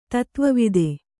♪ tatva vide